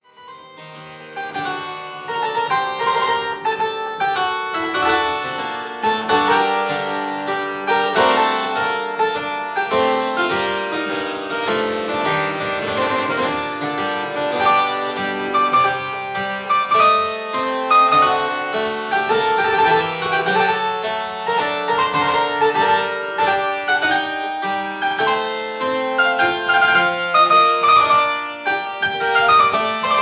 two manual harpsichord